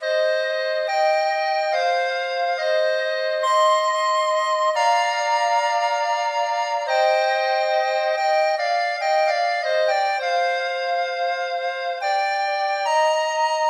标签： 140 bpm Trap Loops Synth Loops 2.31 MB wav Key : Unknown
声道立体声